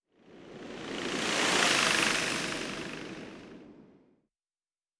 pgs/Assets/Audio/Sci-Fi Sounds/Movement/Fly By 04_4.wav at master
Fly By 04_4.wav